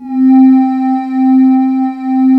Index of /90_sSampleCDs/USB Soundscan vol.28 - Choir Acoustic & Synth [AKAI] 1CD/Partition D/26-VOCOSYNES